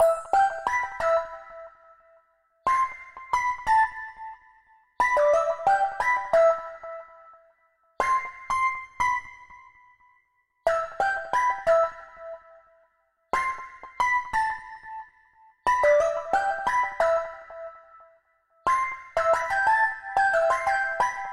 描述：调：Emin 速度：90bpm 一些俱乐部类型的Trap/hiphop循环。
Tag: 90 bpm Rap Loops Synth Loops 3.59 MB wav Key : E